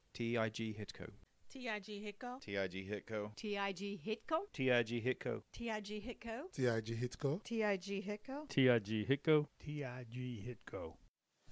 How do you say our company name? Listen to our team members for a quick lesson.
tighitco-pronounced.mp3